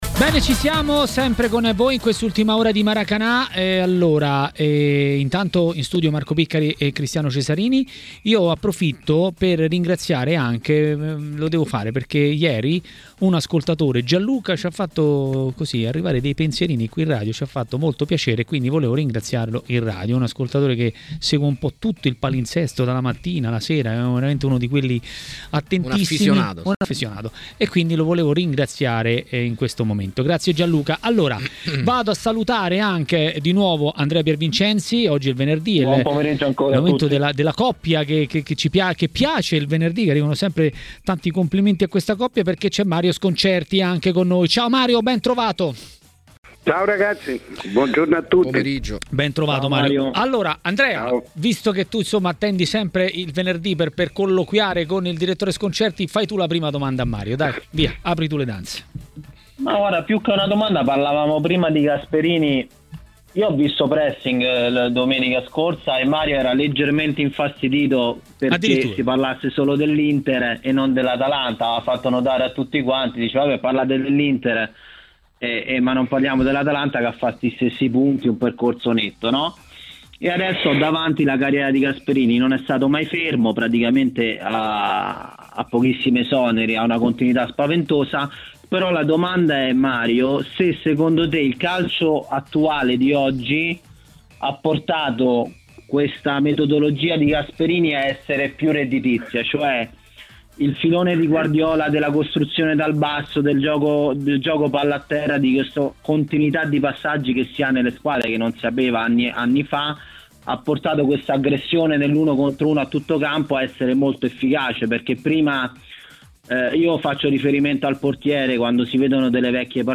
Il direttore Mario Sconcerti, a TMW Radio, durante Maracanà, ha parlato del prossimo turno di Serie A.
TMW Radio Regia Ascolta l'audio Ospite: Mario Sconcerti.